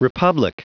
Prononciation du mot republic en anglais (fichier audio)
Prononciation du mot : republic